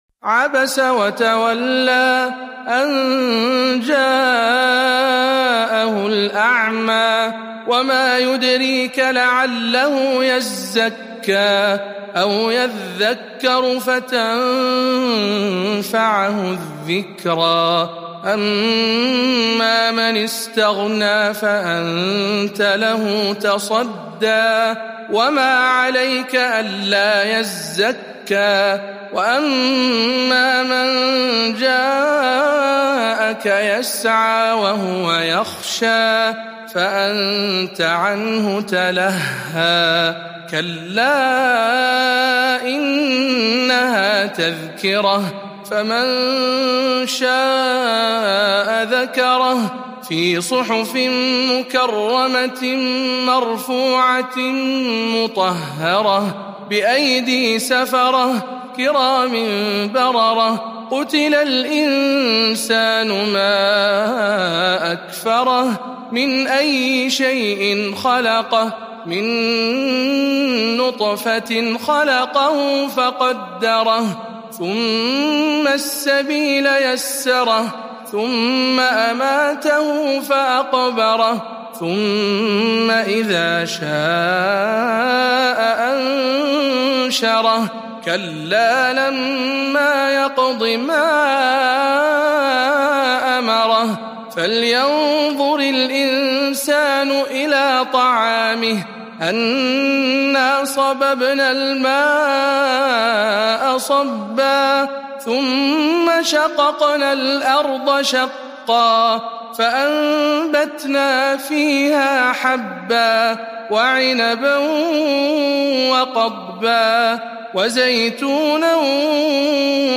079. سورة عبس برواية شعبة عن عاصم